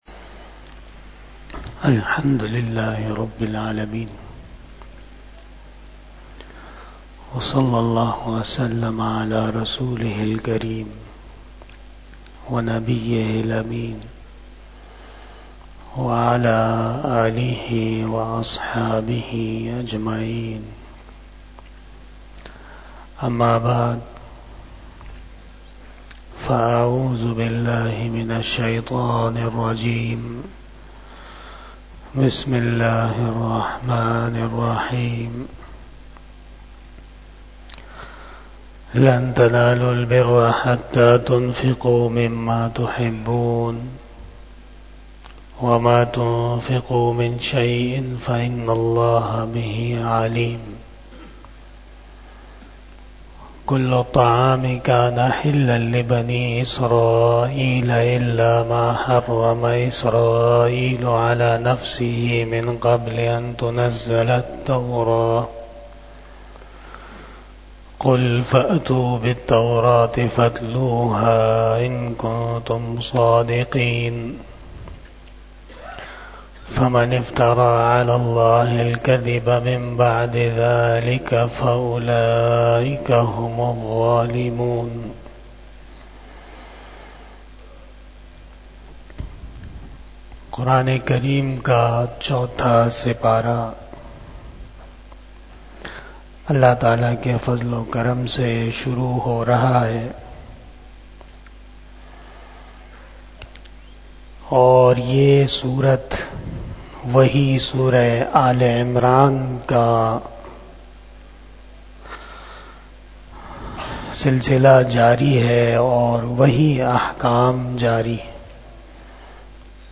01 Shab E Jummah Bayan 04 January 2024 (22 Jamadi Us Sani 1445 HJ)